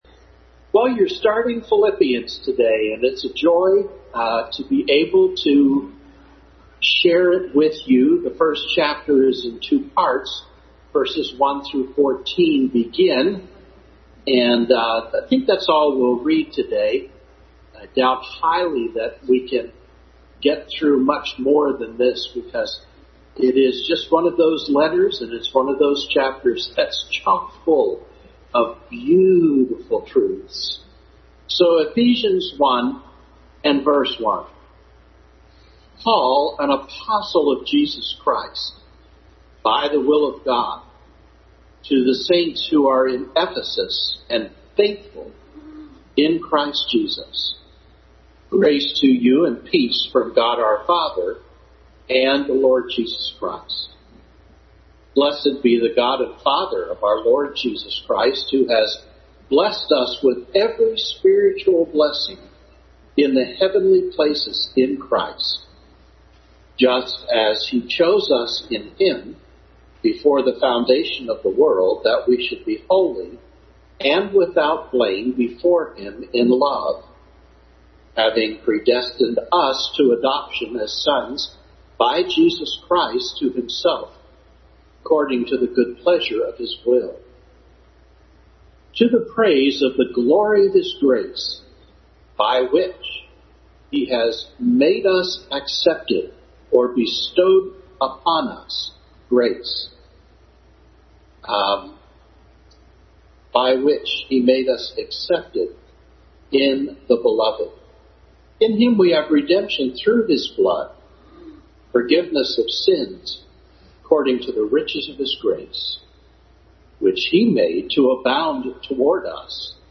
Adult Sunday School beginning a study of Ephesians.
Ephesians 1:1-14 Service Type: Sunday School Adult Sunday School beginning a study of Ephesians.